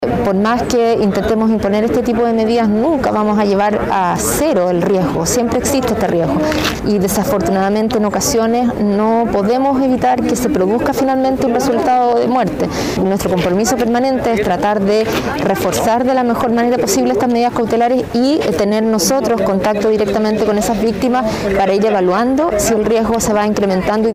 Para la fiscal regional de Valparaíso, Claudia Perivancich, uno de los focos de atención se basa en reforzar las medidas cautelares de los presumibles agresores, a fin de que este fatal número no incremente.
cu-femicidios-fiscal.mp3